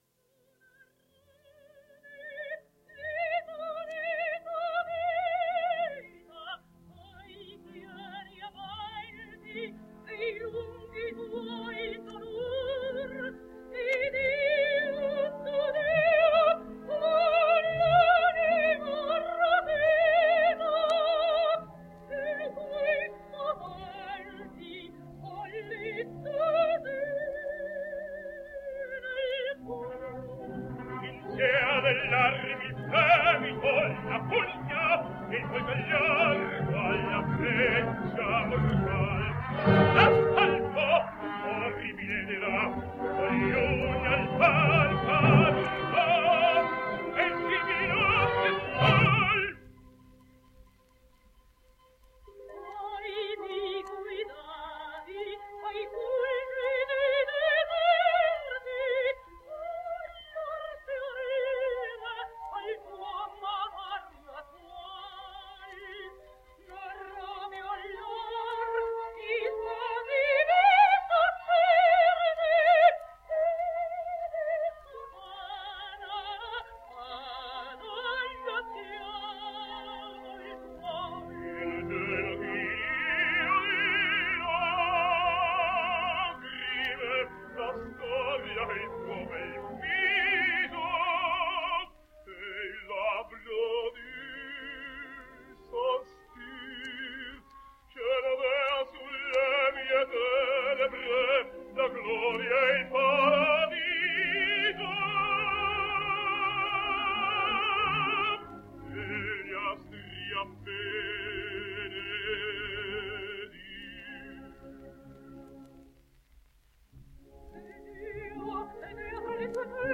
Italian tenor.
I should like to finish, with part of a duet from Othello, his most popular role and it also gives us the opportunity to hear the devine Claudio Mutzi.